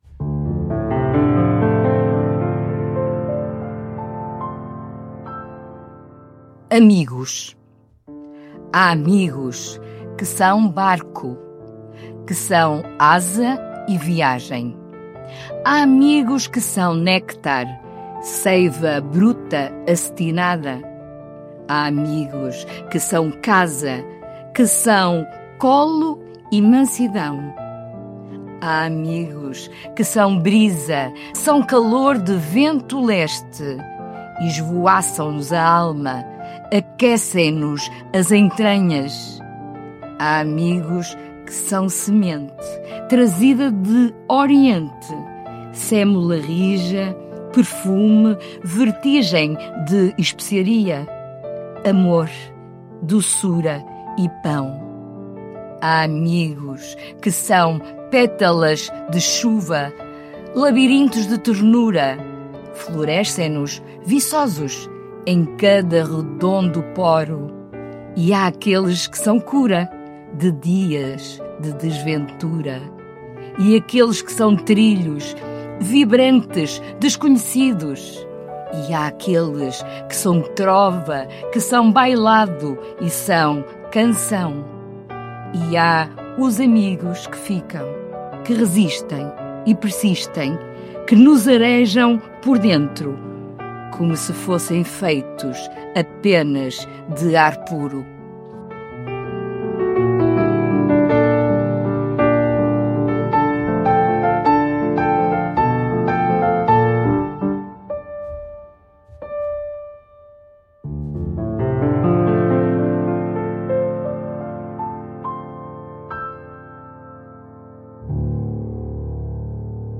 Música: Classical Piano Waltz, por RND Music, Licença Envato – Free Files Single Use Policy.